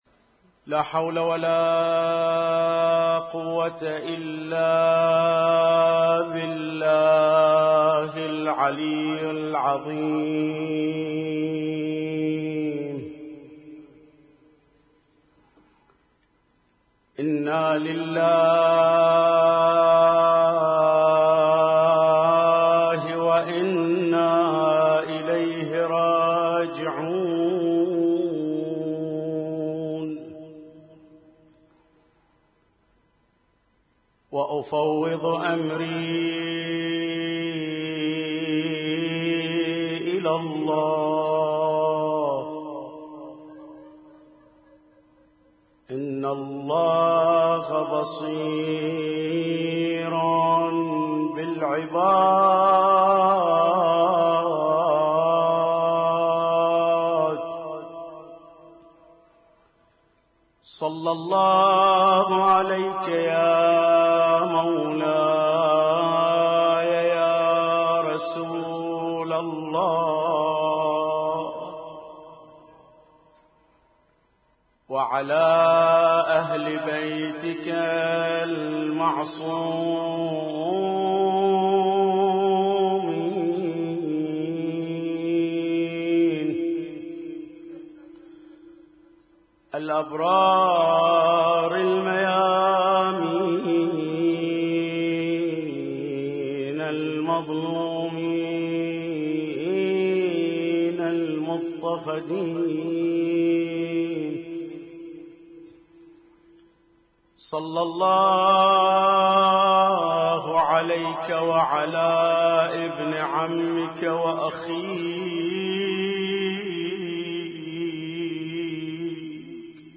Husainyt Alnoor Rumaithiya Kuwait